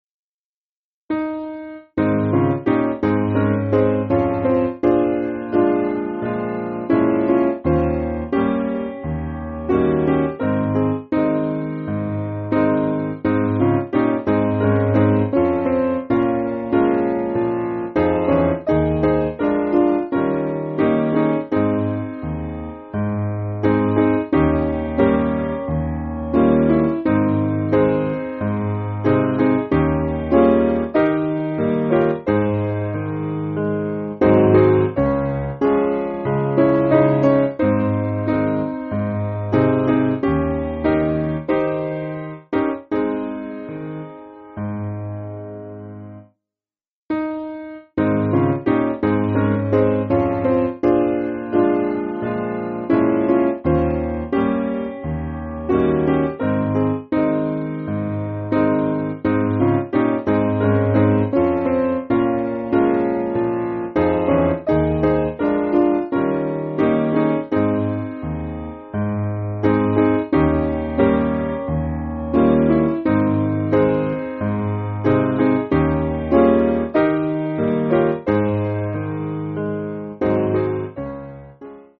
Simple Piano
(CM)   3/Ab